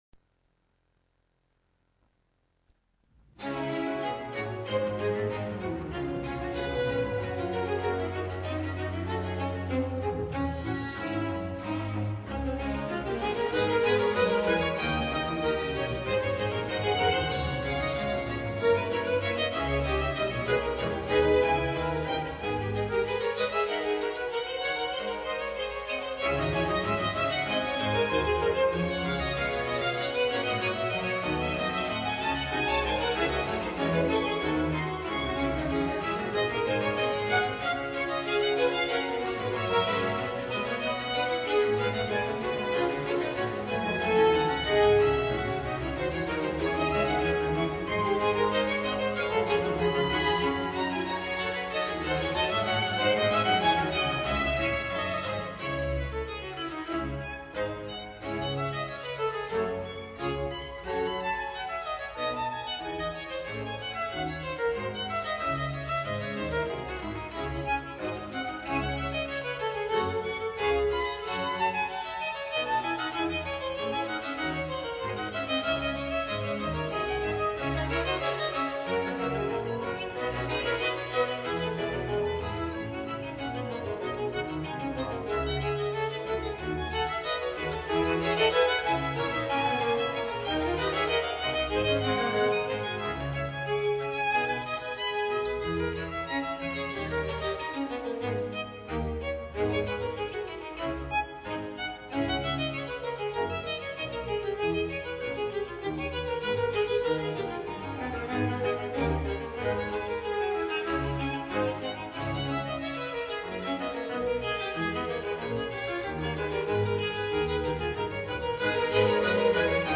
这首作品中双小提琴交替进行,其手法重在炫耀主奏乐器独特的演奏技巧和华丽的装饰音， 深刻体现了巴洛克音乐那种富于装饰的风格。 第二乐章呈现出极优美的旋律，接近近代协奏曲的抒情趣味，使乐曲倍增亲切感人的情趣。乐曲共分三个乐章： 第一乐章,活泼的快板，d小调，2/2拍子，以弦乐合奏的总奏开始， 开头的主题先由第二小提琴呈示，自第五小节开始，才由第一小提琴模仿进入（片段1）； ================================================ & 下载链接需登录后回复可见 & & 注：聆听一辑分区需音乐精灵登陆后回复可见 & ================================================ 转贴的朋友请注明出处！